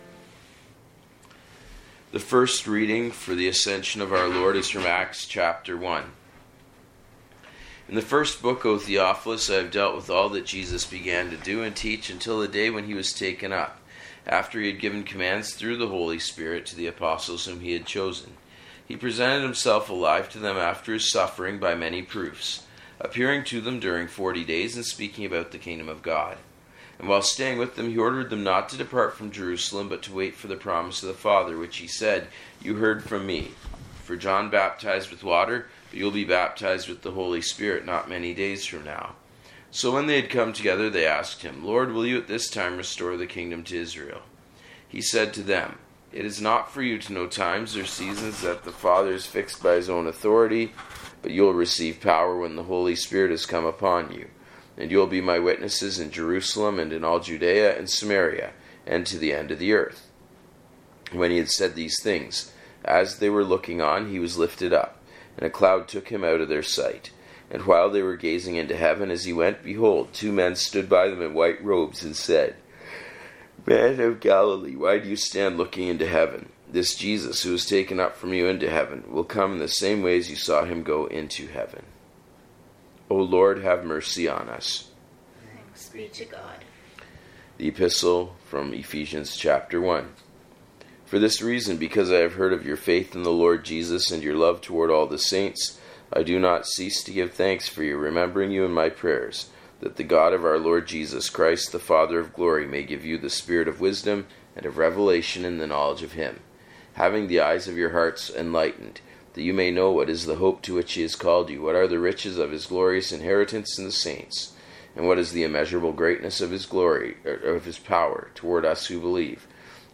Readings and Sermon – May 29, 2025